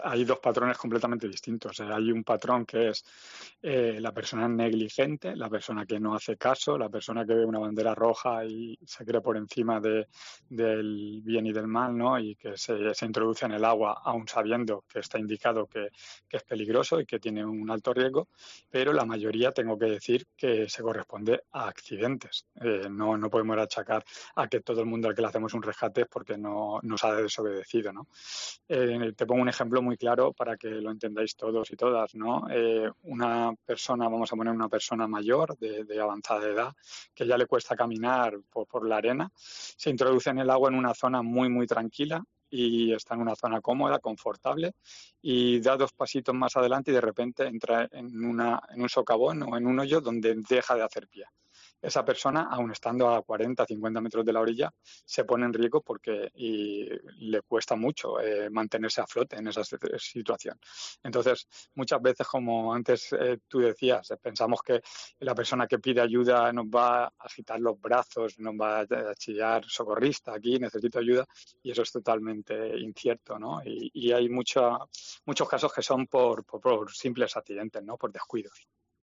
Un responsable de salvamento explica qué debemos hacer para ayudar a alguien que se esta ahogando